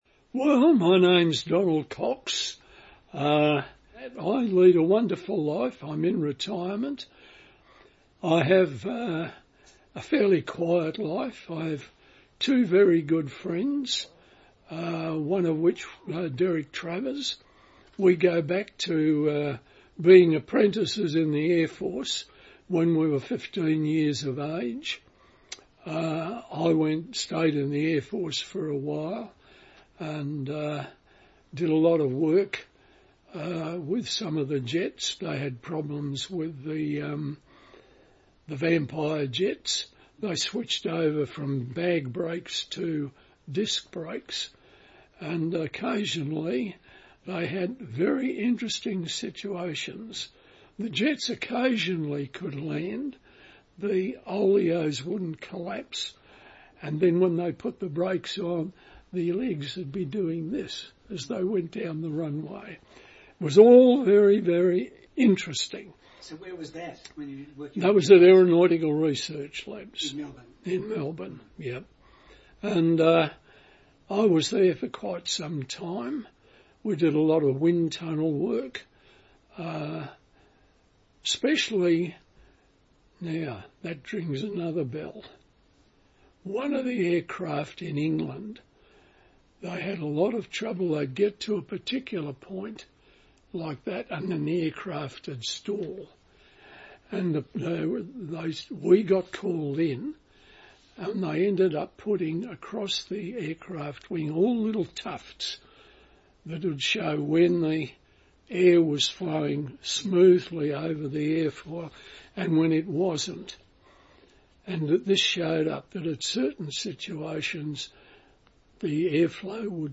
Interview
recorded in Canberra, October 2023.